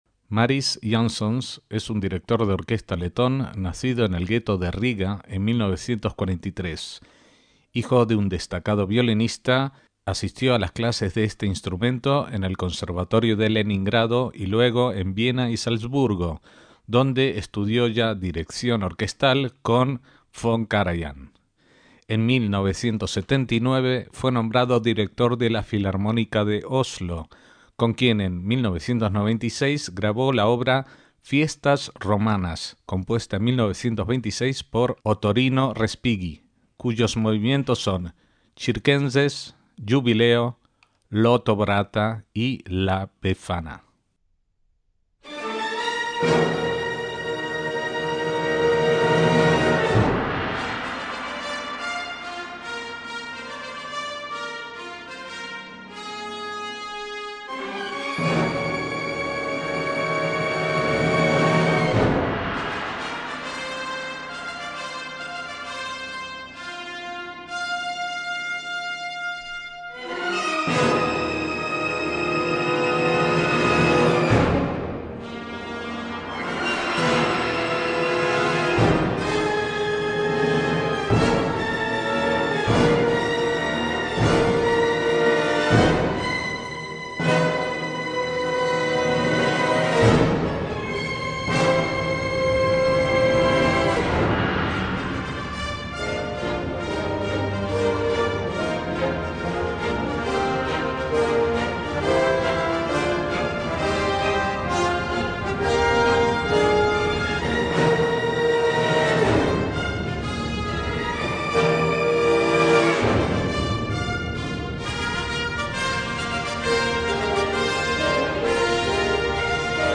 MÚSICA CLÁSICA
poema sinfónico